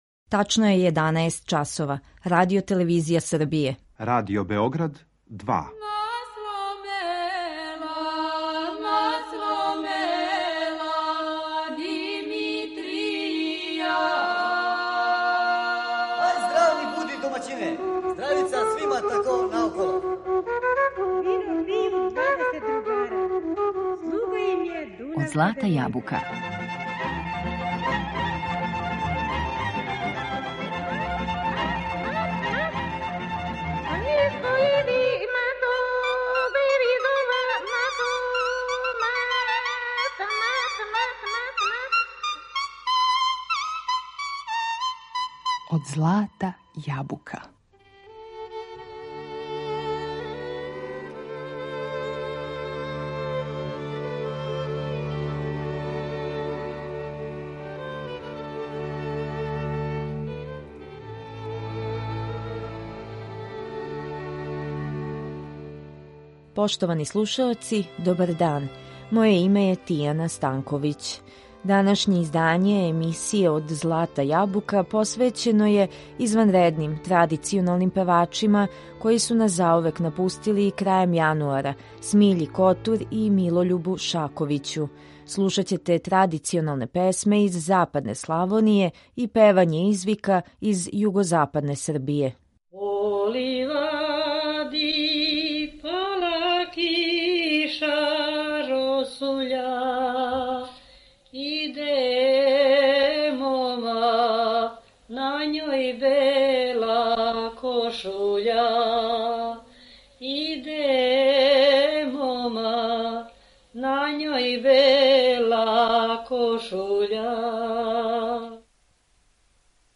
изванредним традиционалним певачима
различите вокалне облике традиционалних песама западне Слaвoније